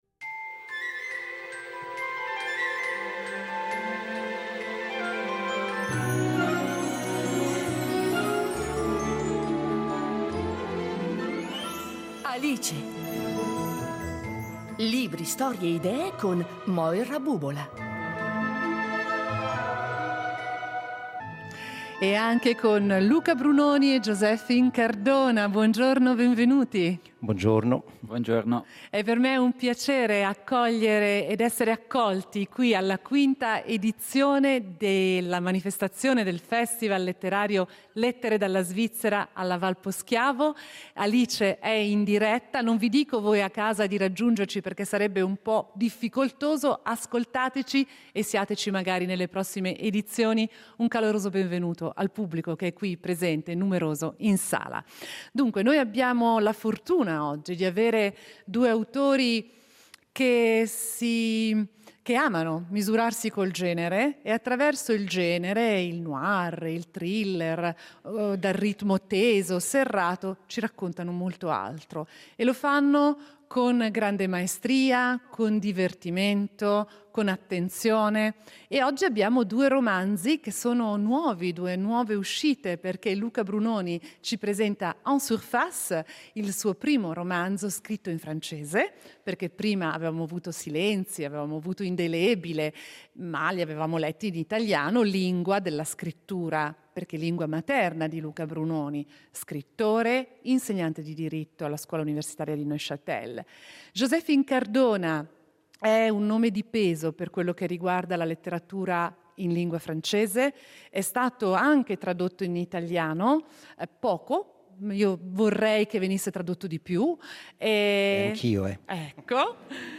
La sfida della scrittura: in diretta da Poschiavo